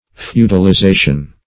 Feudalization \Feu`dal*i*za"tion\, n.